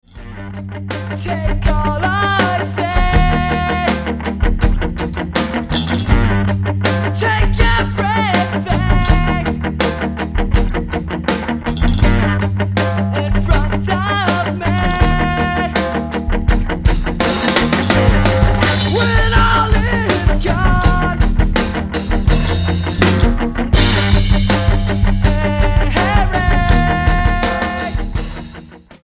STYLE: Rock
abrasive, post punk, emo rock